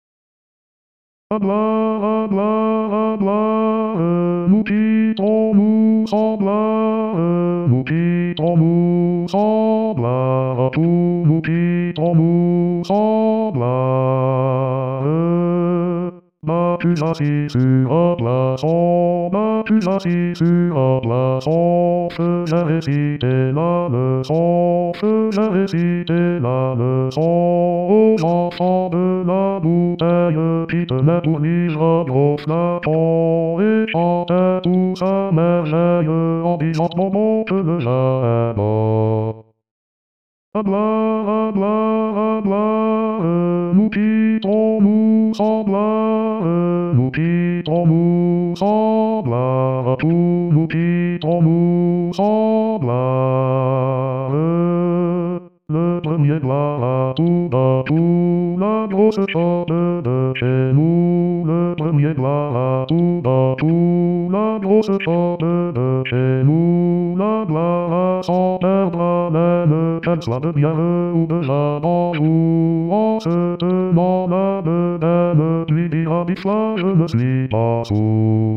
A partir de cette page vous accédez à l'ensemble des fichiers karaoké de la saison en cours qui sont disponibles pour chaque pupitre.
Les abréviations sont les suivantes: A = Altis, B= Basses, C = Choeur, F= Femmes, H= Hommes, S = Sopranes et T = Ténors; le signe + indique les fichiers où les autres voix sont en accompagnement.